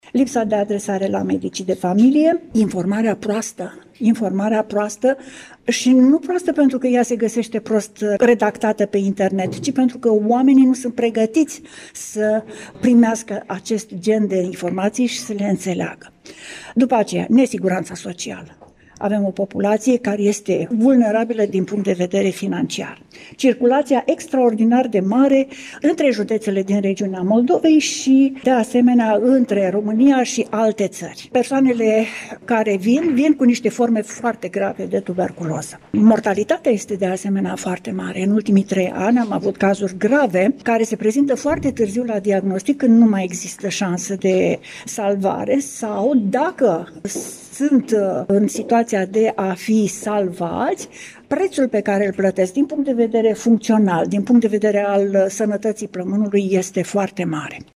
Astăzi, într-o conferință de presă organizată cu prilejul Zilei Mondiale de Luptă împotriva Tuberculozei